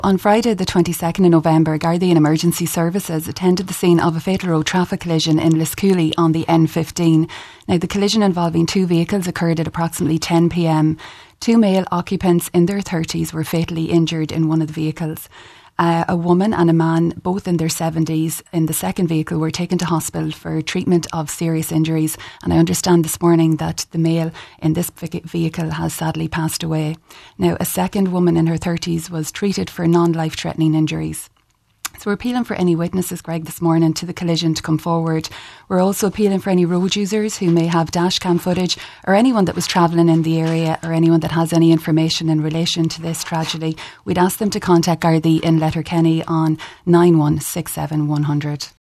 made the following appeal: